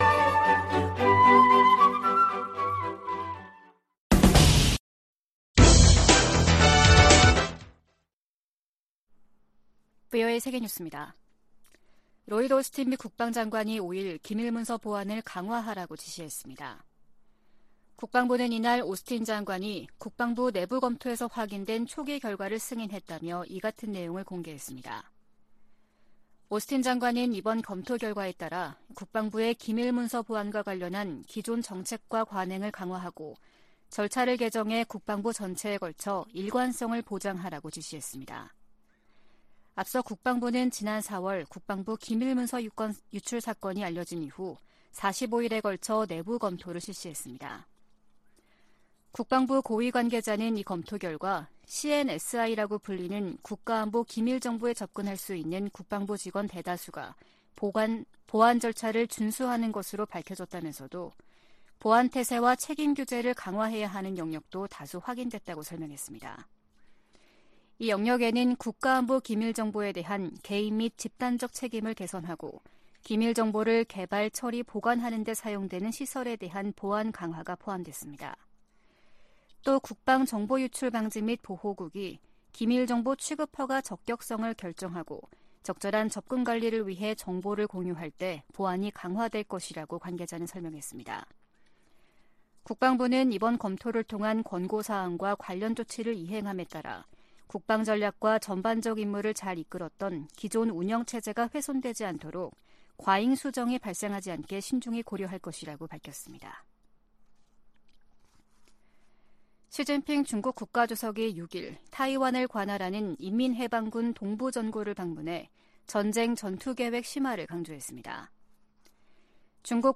VOA 한국어 아침 뉴스 프로그램 '워싱턴 뉴스 광장' 2023년 7월 7일 방송입니다. 한국 군 당국이 서해에서 인양한 북한의 정찰위성을 분석한 결과 군사적 효용성이 전혀 없다고 평가했습니다. 한중 외교 당국 대화가 재개된 것은 '위험 관리'에 들어간 최근 미중 간 기류와 무관하지 않다고 미국의 전직 관리들이 진단했습니다. 중국의 반간첩법 개정안은 중국 내 탈북민 구출 활동을 봉쇄할 수 있는 악법이라고 탈북 지원단체들과 브로커들이 말했습니다.